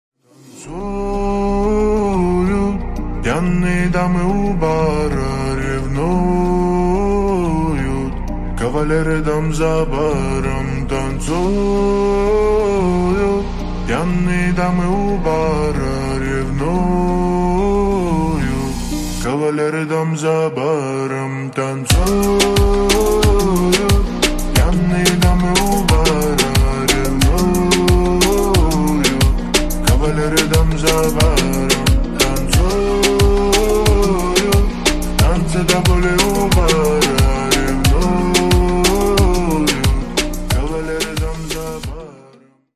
спокойные